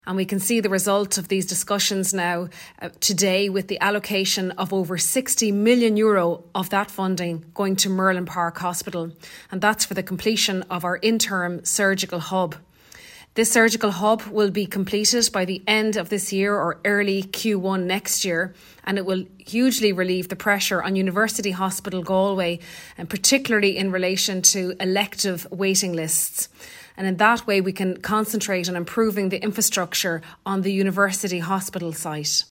Galway West TD and Junior Minister Hildegarde Naughton says the separation of urgent and elective care is very important.